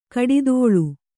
♪ kaḍidōḷu